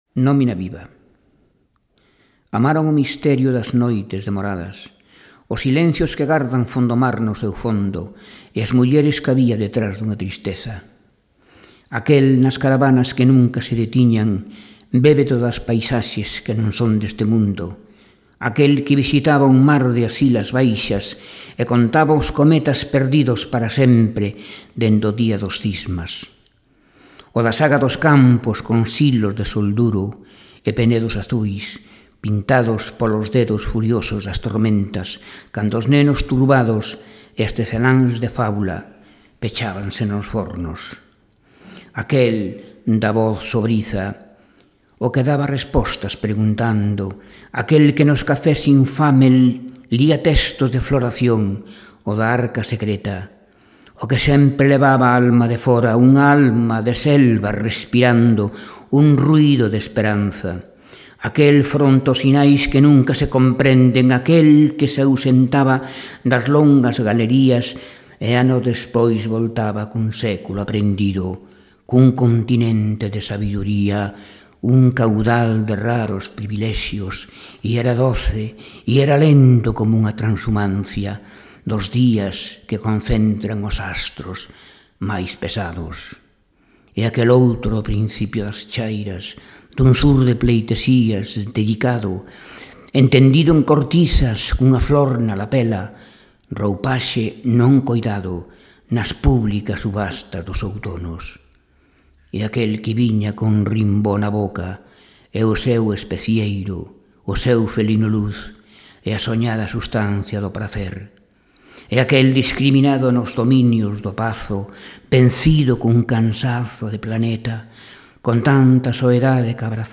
Gravaci�n pertencente ao volume 2 de Poetas e narradores nas s�as voces , publicado polo Arquivo Sonoro de Galicia (Santiago de Compostela: Consello da Cultura Galega, 2006).